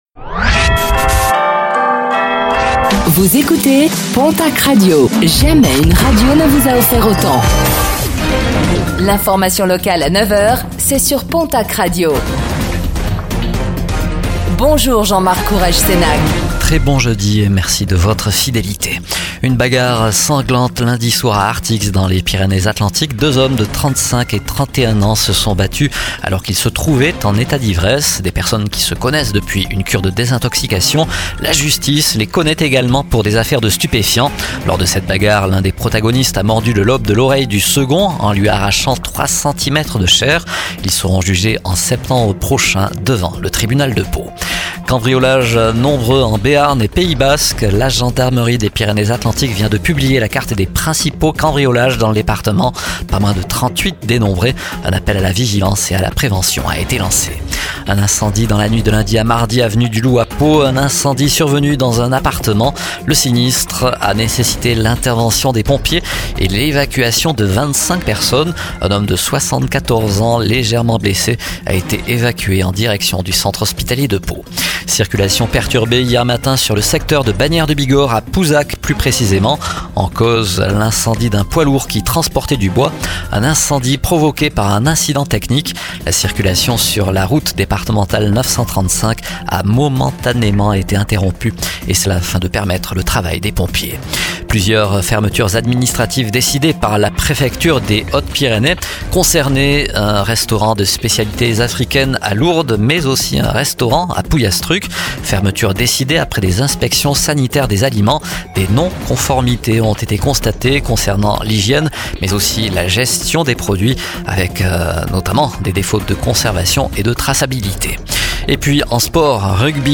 Réécoutez le flash d'information locale de ce jeudi 22 mai 2025